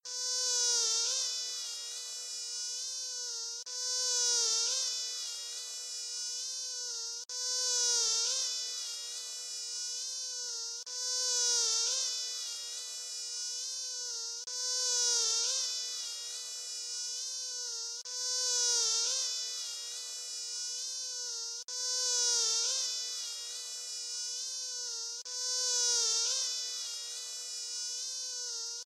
Mosquito+Buzzing.mp3